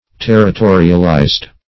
Search Result for " territorialized" : The Collaborative International Dictionary of English v.0.48: Territorialize \Ter`ri*to"ri*al*ize\, v. t. [imp.